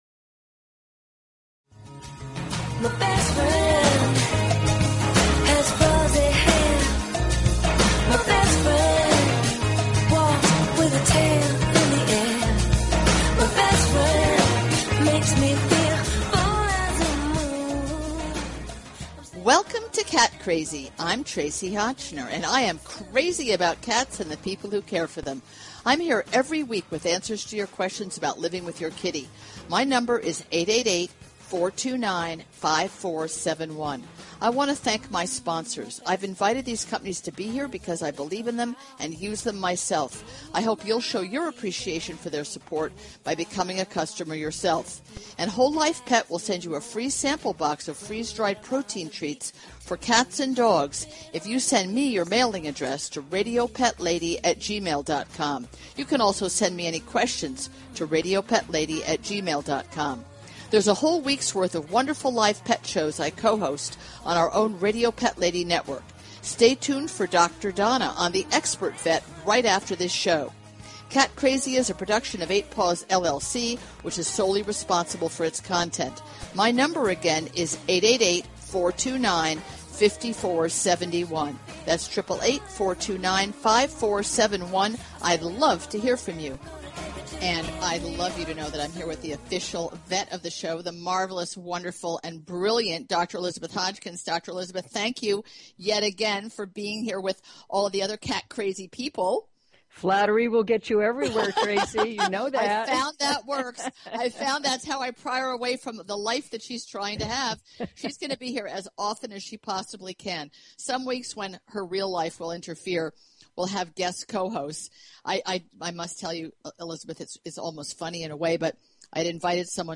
Talk Show Episode, Audio Podcast, Cat_Crazy and Courtesy of BBS Radio on , show guests , about , categorized as